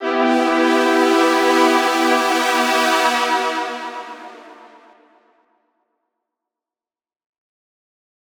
Chords_E_03.wav